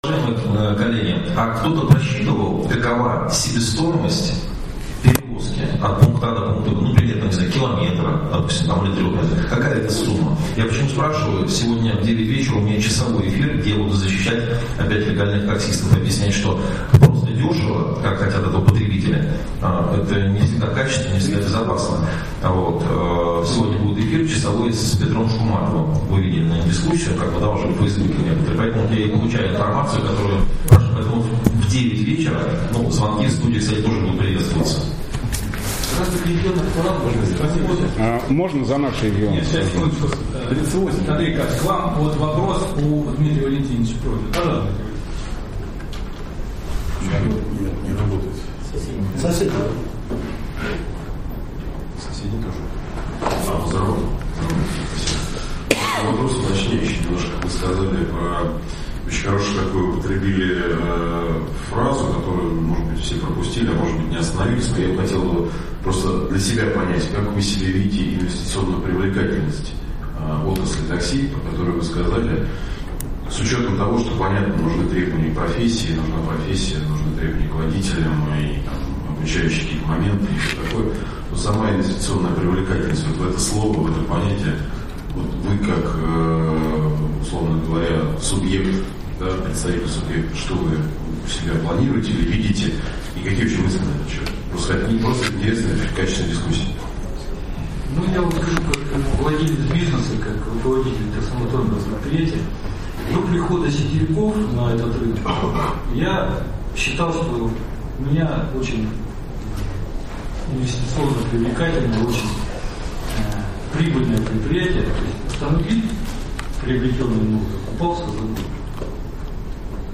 Круглый стол по законодательству о такси в АЦ Правительства РФ (10)